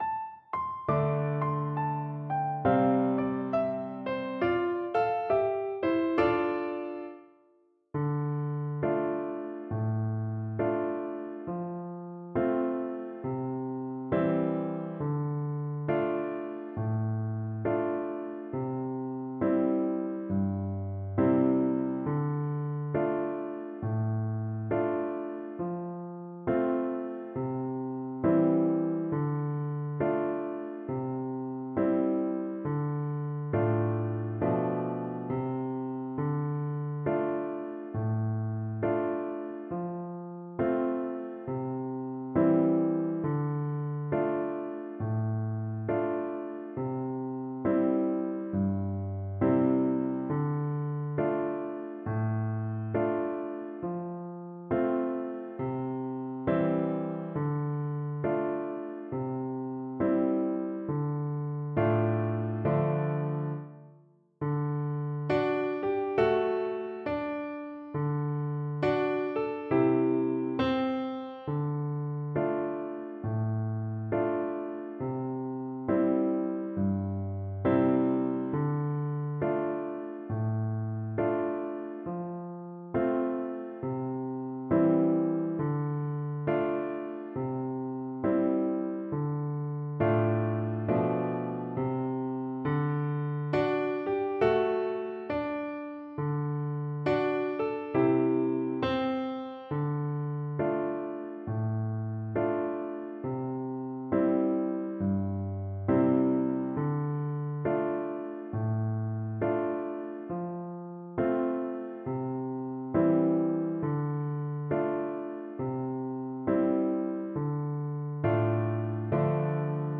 Play (or use space bar on your keyboard) Pause Music Playalong - Piano Accompaniment transpose reset tempo print settings full screen
Flute
D minor (Sounding Pitch) (View more D minor Music for Flute )
2/2 (View more 2/2 Music)
With a swing = c.50
Hornpipes for Flute